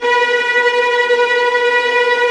VIOLINT C#-L.wav